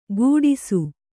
♪ gūḍisu